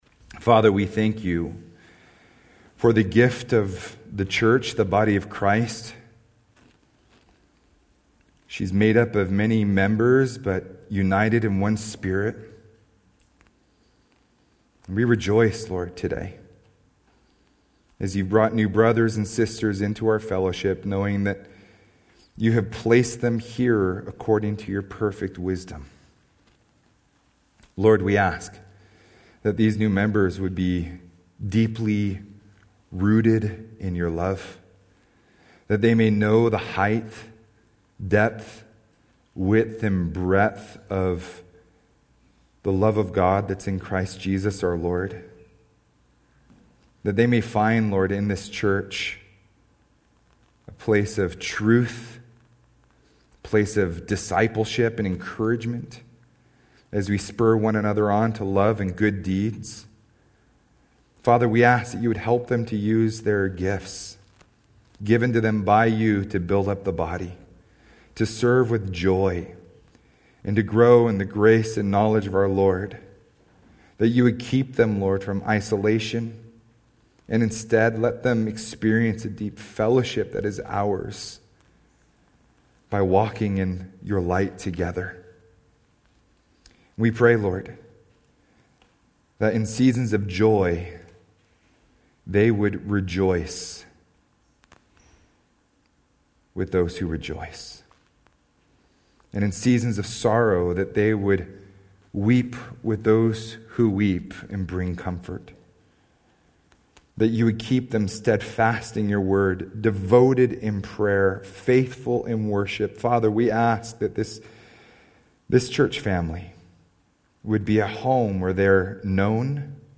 Sermons - Solid Rock Christian Fellowship